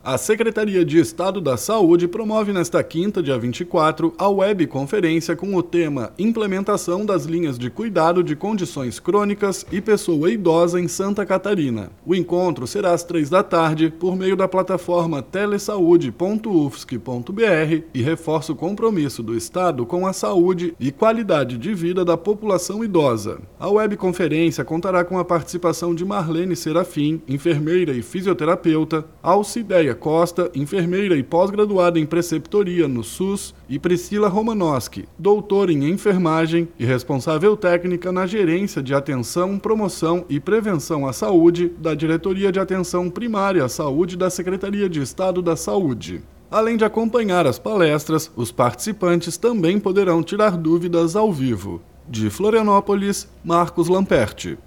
BOLETIM – Webconferência discute Implementação das Linhas de Cuidado de Condições Crônicas e Pessoa Idosa em SC